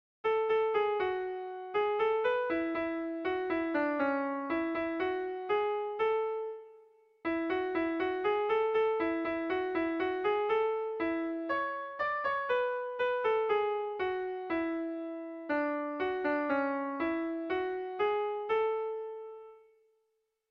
Irrizkoa
Sei puntuko berezia
ABDEFG